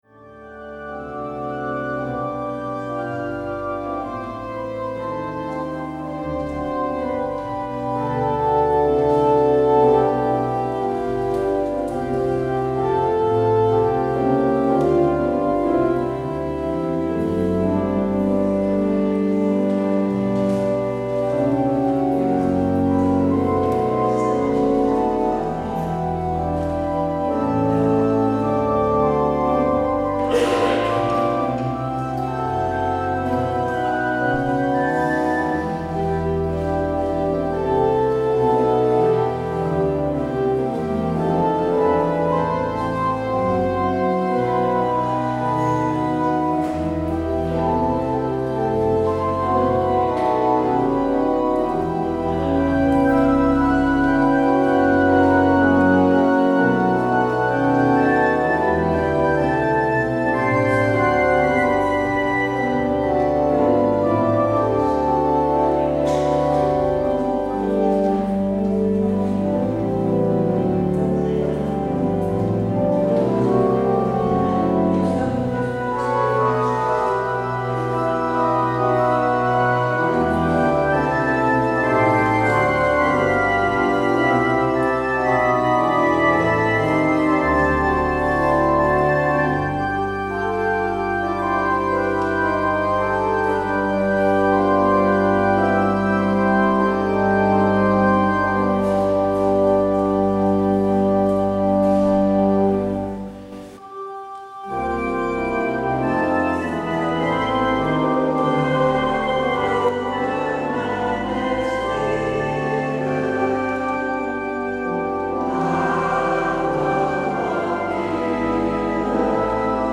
Vandaag de lekenpreek
kerkdienst